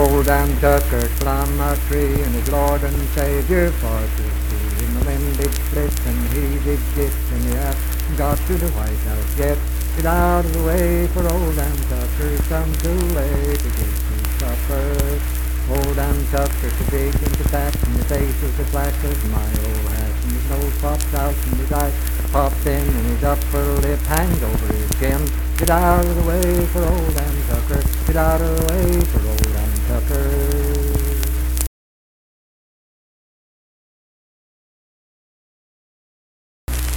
Unaccompanied vocal music
Dance, Game, and Party Songs
Voice (sung)